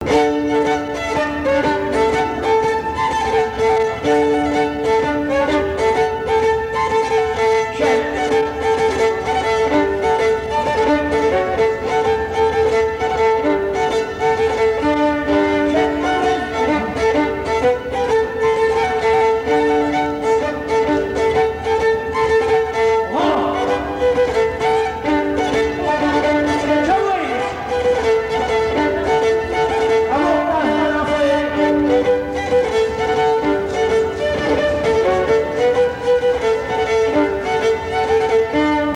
danse : quadrille : pastourelle
Pièce musicale inédite